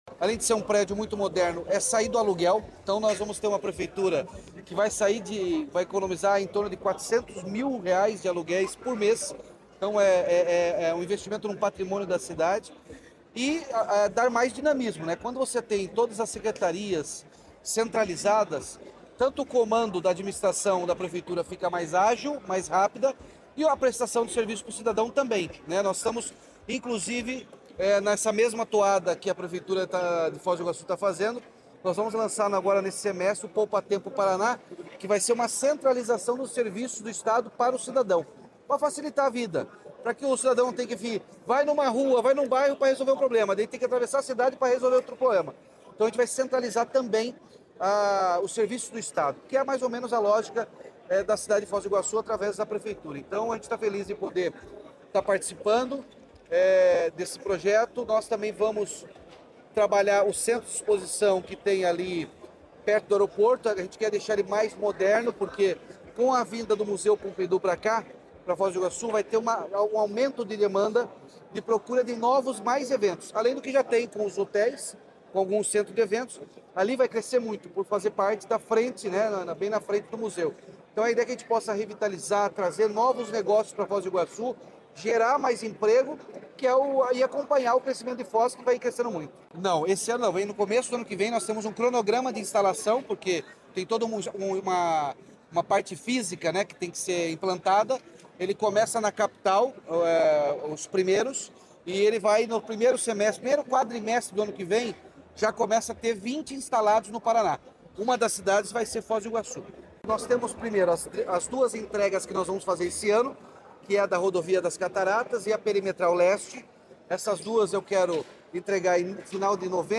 Sonora do governador Ratinho Junior sobre o Centro Cívico Administrativo de Foz do Iguaçu